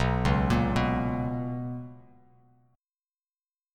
BM7sus2 chord